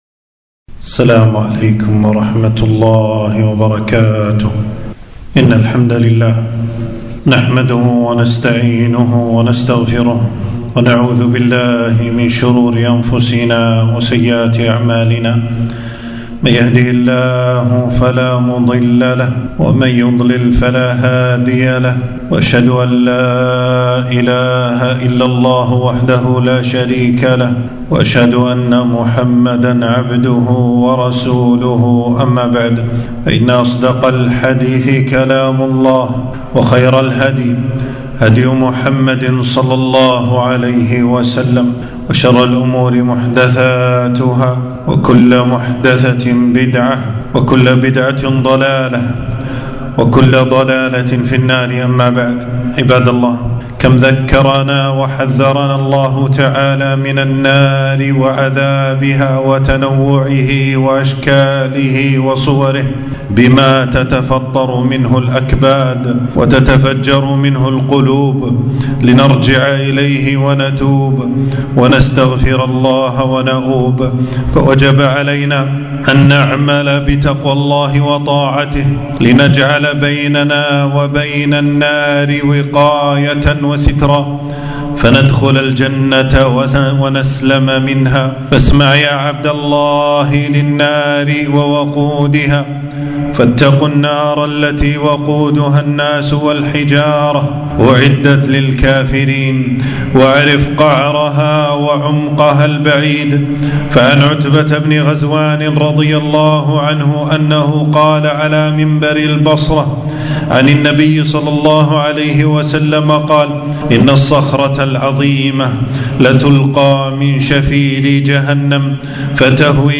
خطبة - شيء من عذاب أهل النار ونعيم أهل الجنة